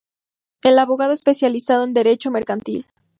Uitgesproken als (IPA)
/meɾkanˈtil/